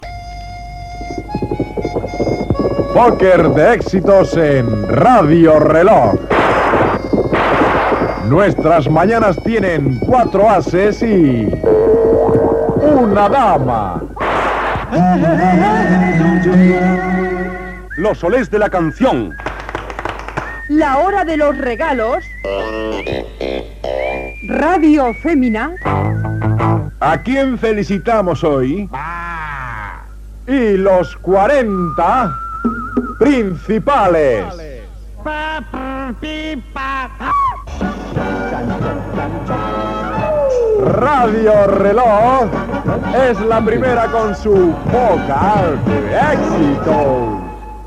Promo "pocker de éxitos" anunciant quatre programes matinals de l'emissora.